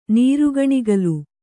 ♪ nīrugaṇigalu